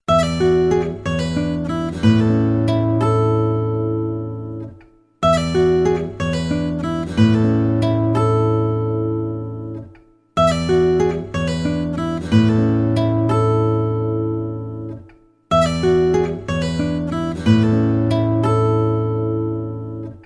Category: Guitar Ringtones